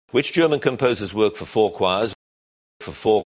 A good way to illustrate this is with real utterances in which native speakers say the words for four in sequence: /fə fɔː/ or /f fɔː/. Notice the weakness of for and the prominence of four: